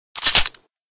handgun_unjam.ogg